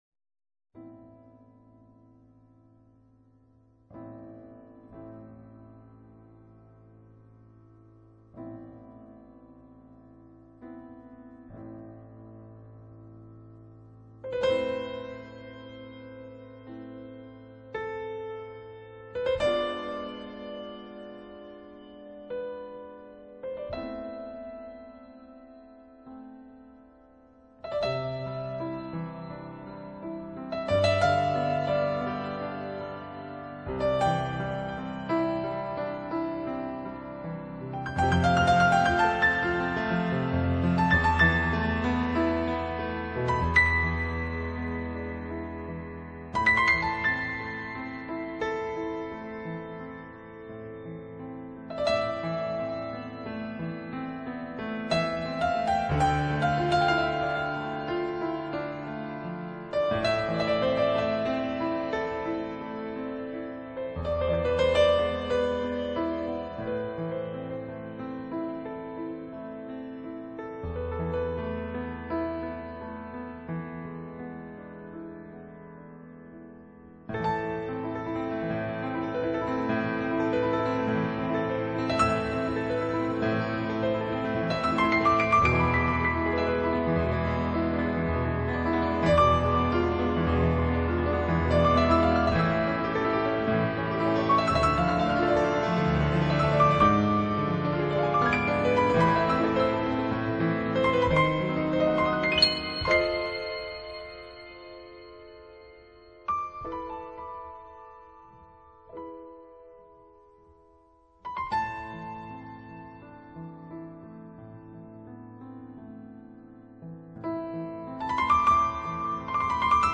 类型：New Age
轻柔的吉它、优雅的长笛佐以婉约的钢琴，
坚持真爱的女子在音符流转中闪闪动人，在器乐和鸣中轻轻摇曳。